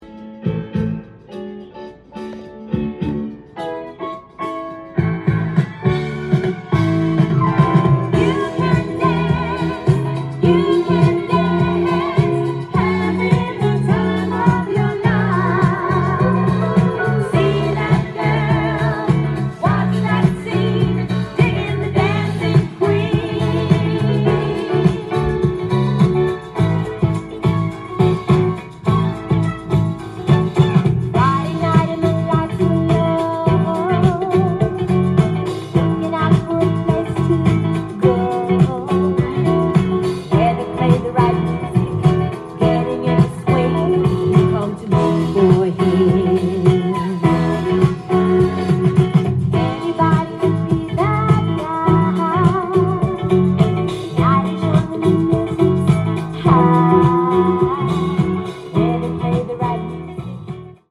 ジャンル：Soul-7inch-全商品250円
店頭で録音した音源の為、多少の外部音や音質の悪さはございますが、サンプルとしてご視聴ください。
音が稀にチリ・プツ出る程度